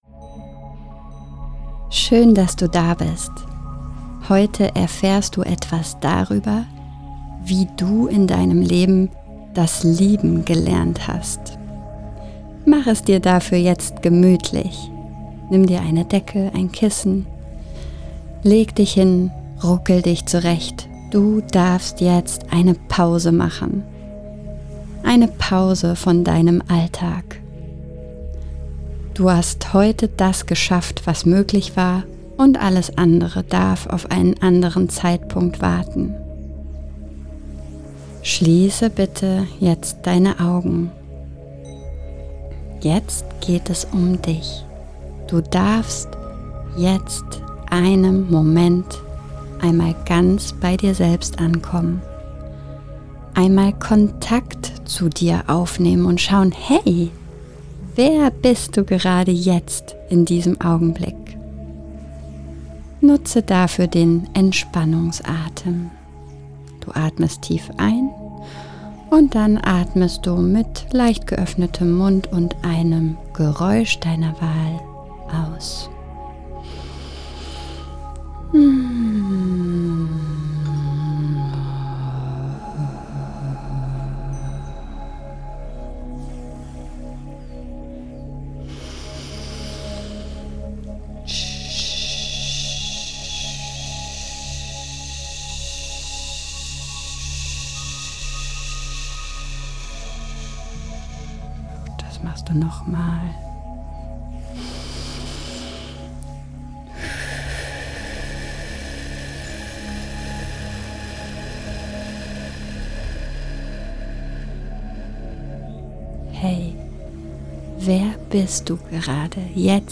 Deine-Liebesvorbilder-MEDITATION-1.mp3